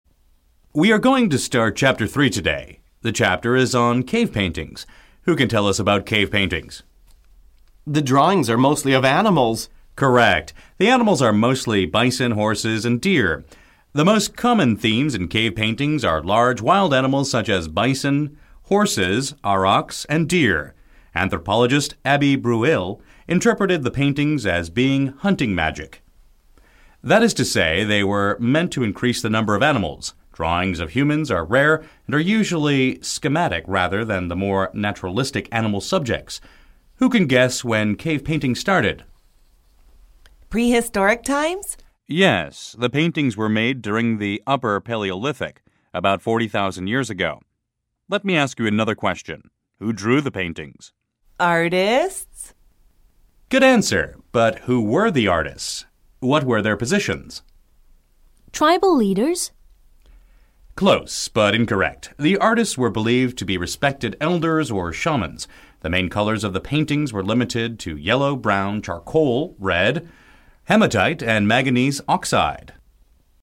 Cave Paintings – Retell Lecture